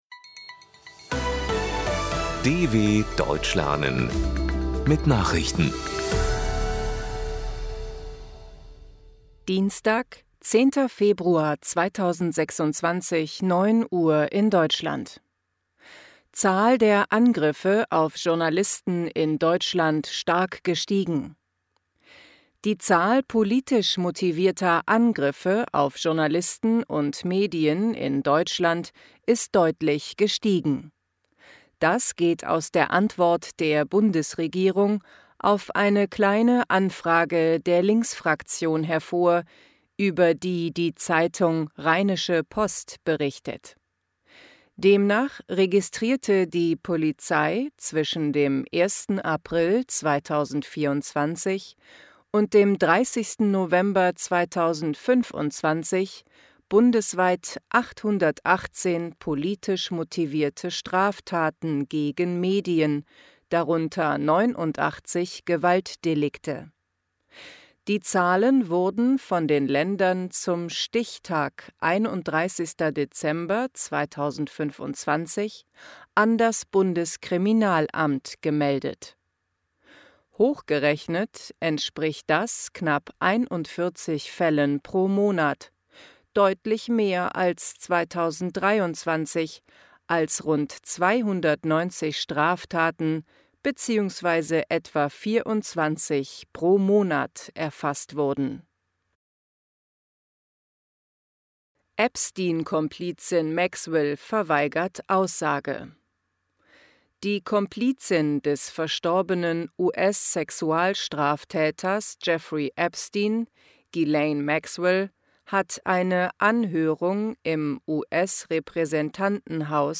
10.02.2026 – Langsam Gesprochene Nachrichten
B2 | Deutsch für Fortgeschrittene: Verbessert euer Deutsch mit aktuellen Tagesnachrichten der Deutschen Welle – für Deutschlerner besonders langsam und deutlich gesprochen.